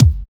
THUMPY KICK.wav